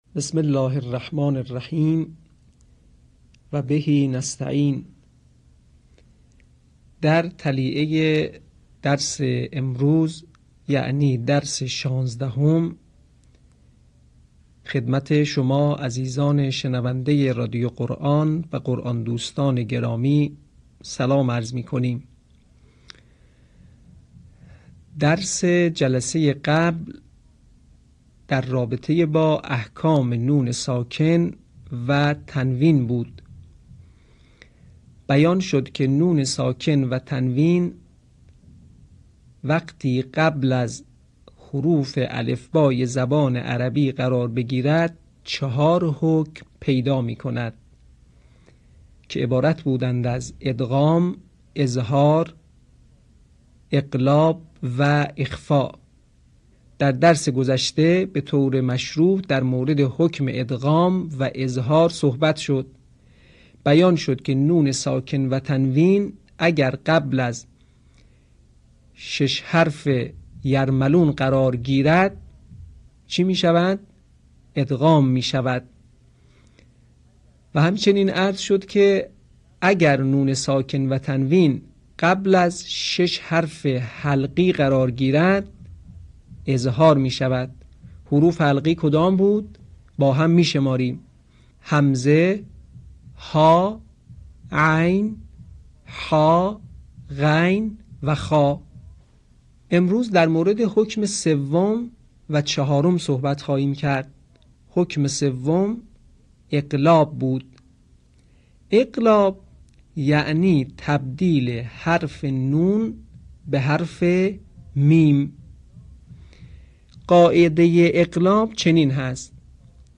صوت | آموزش احکام نون ساکن